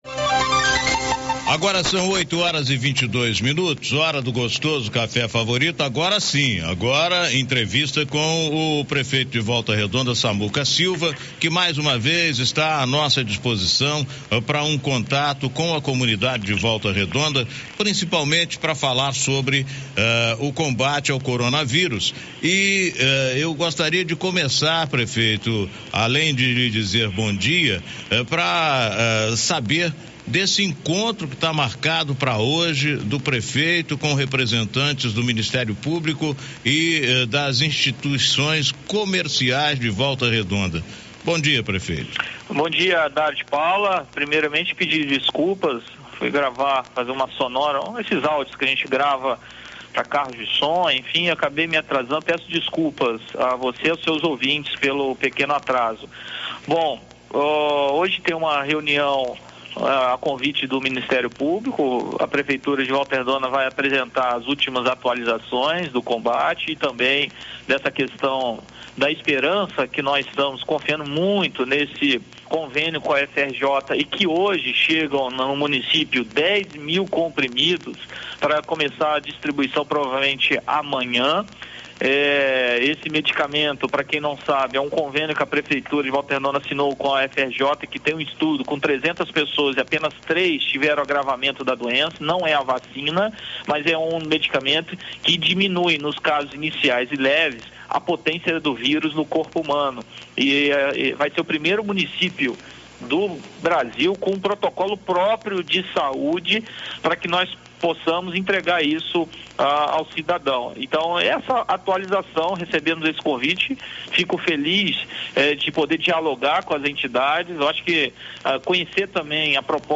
entrevista_audio.mp3